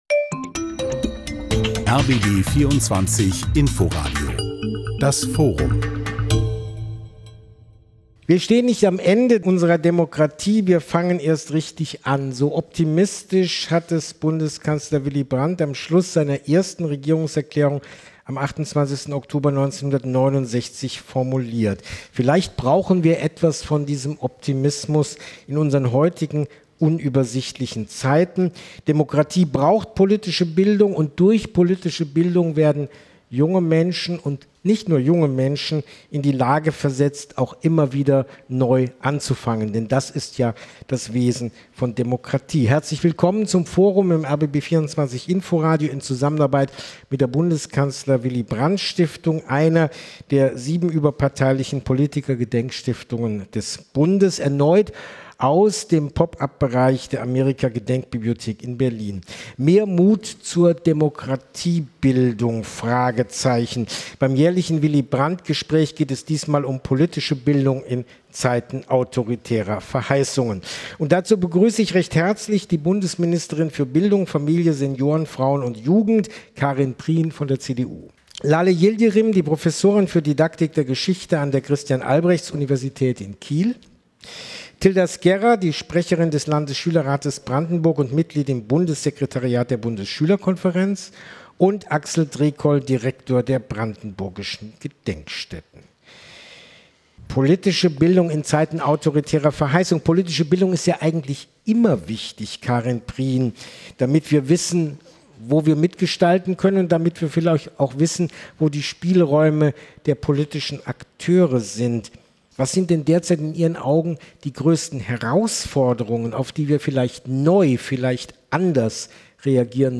diskutiert mit seinen Gästen.